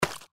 Index of /server/sound/npc/gecko/foot
fs_gecko_r03.mp3